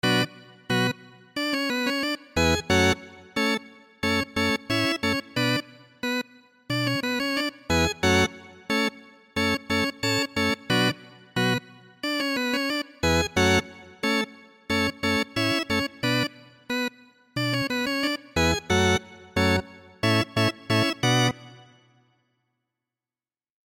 2、Solo Trumpet（独奏小号）
GSi Solo Trumpet 是小号的物理建模仿真。
Solo Trumpet 不断分析演奏风格、速度、断奏和连奏、音符距离和其他元素的使用，以便以自然和逼真的方式表达声音。